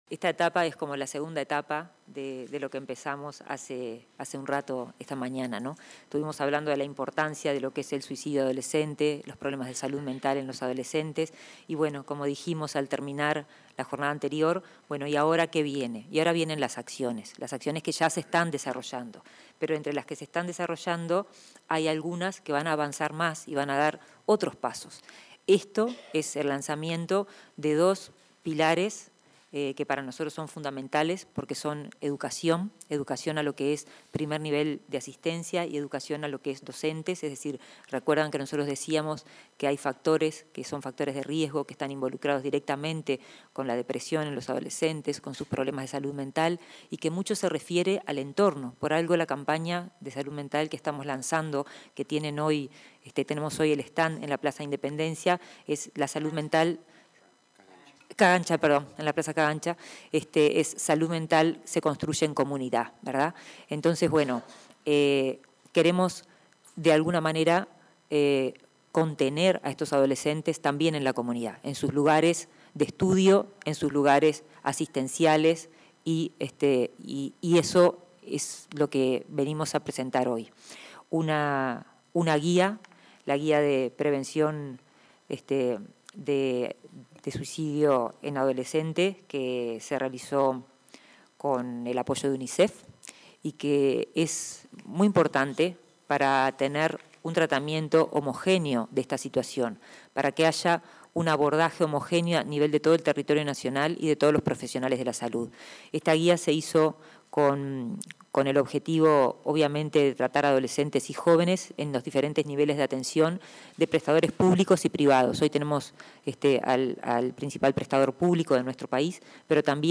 Palabras de autoridades en acto en el MSP
Palabras de autoridades en acto en el MSP 10/10/2023 Compartir Facebook X Copiar enlace WhatsApp LinkedIn Este martes 10 en el Ministerio de Salud Pública (MSP), la titular de la cartera, Karina Rando; el presidente de la Administración de los Servicios de Salud del Estado, Leonardo Cipriani, y el titular de la Administración Nacional de Educación Pública, Robert Silva, participaron en la presentación de un manual de prevención del suicidio en adolescentes.